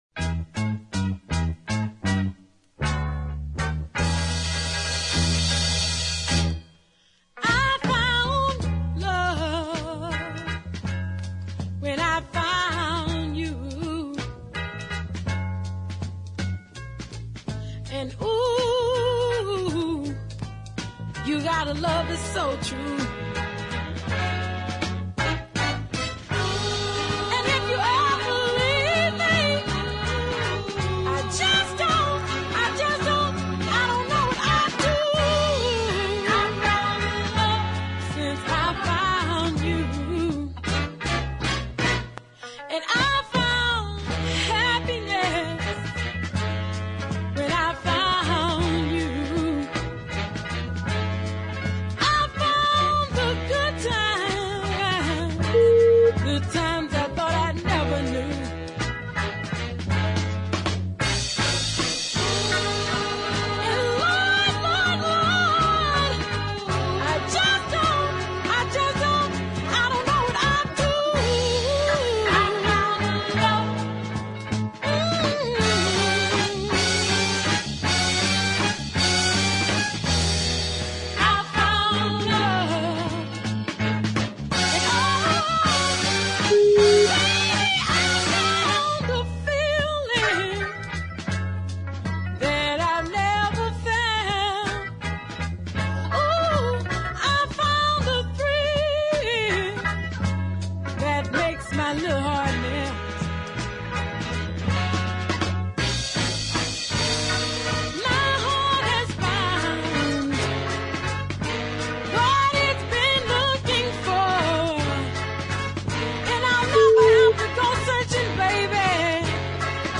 deep soul ballad
the horns are nicely prominent